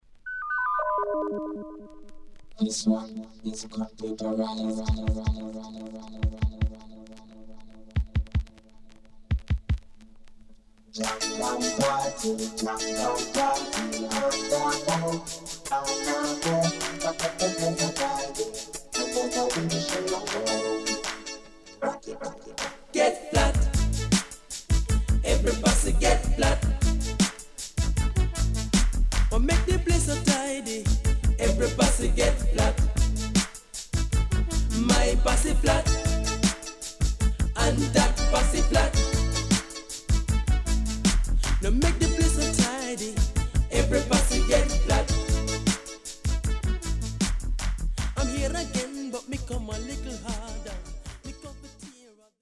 HOME > Back Order [DANCEHALL DISCO45]
riddim
SIDE A:少しチリノイズ、プチノイズ入ります。